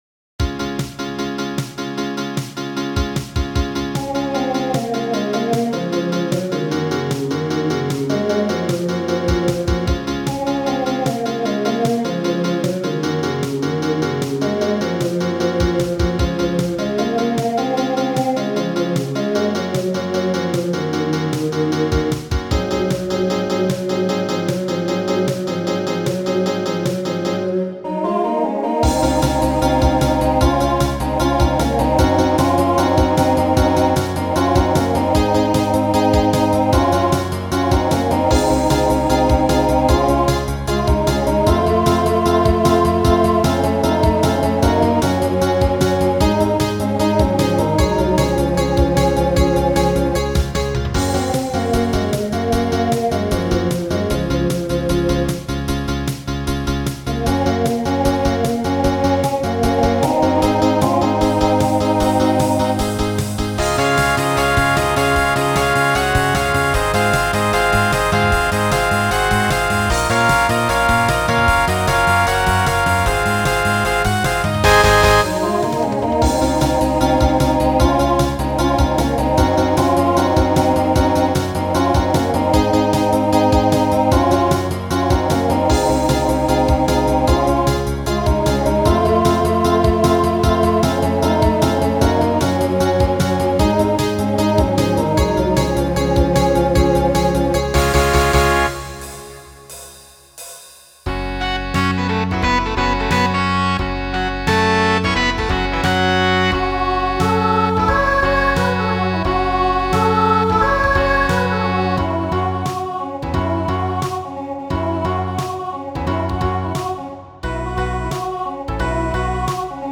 TTB/SSA
Voicing Mixed Instrumental combo Genre Country , Pop/Dance